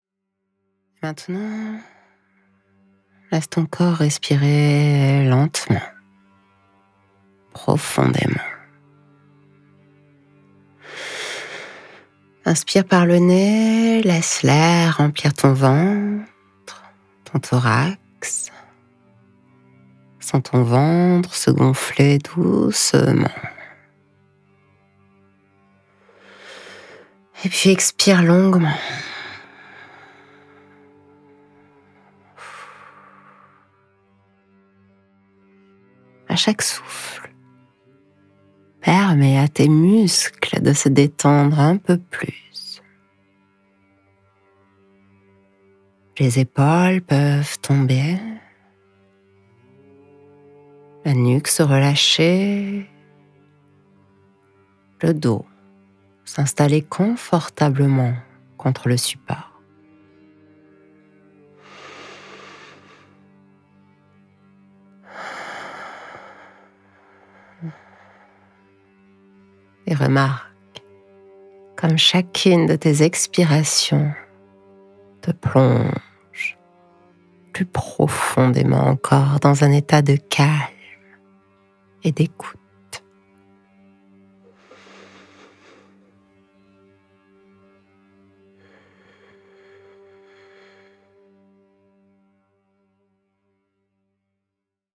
👉 Une séance d’hypnose guidée spécialement conçue pour les personnes atteintes d’une maladie chronique, dont le Covid Long, pour retrouver souffle, apaisement et lien à soi.
Voici un extrait d’une séance pour entendre la voix qui va vous accompagner durant la séance